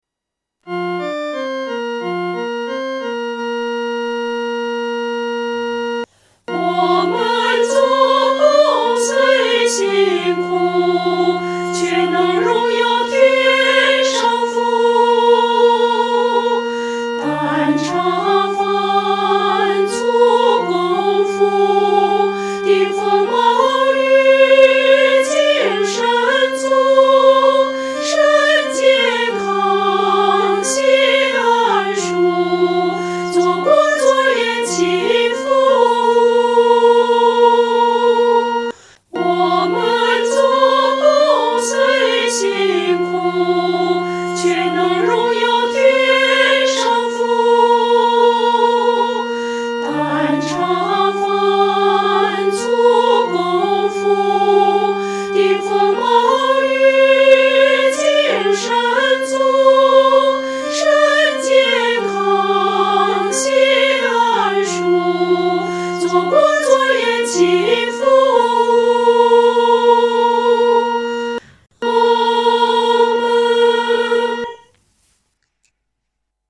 女高
曲调很朴素，用的是五声音阶，农村信徒很容易上口。